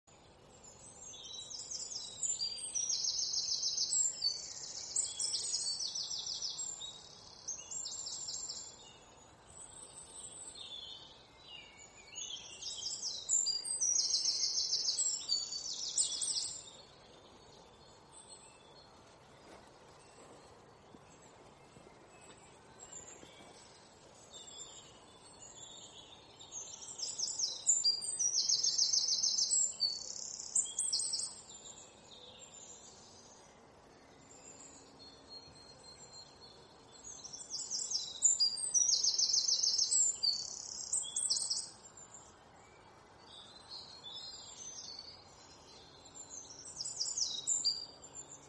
Suara Alam.mp3